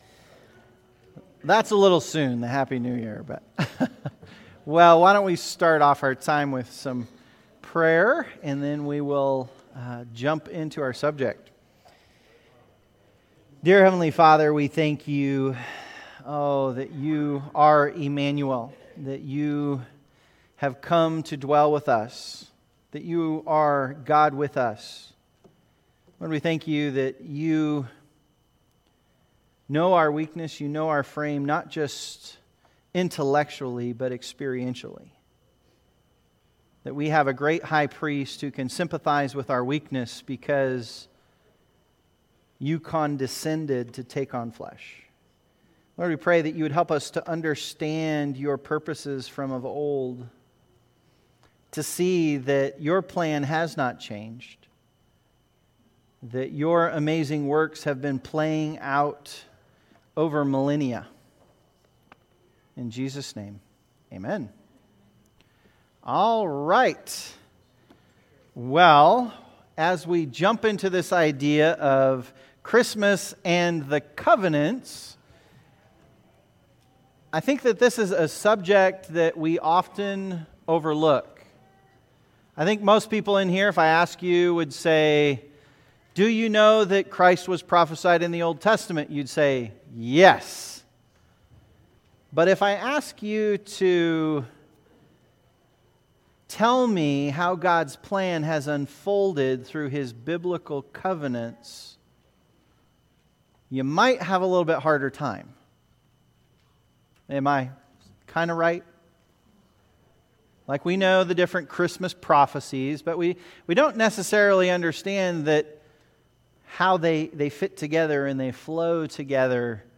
Adult Sunday School - 12/15/24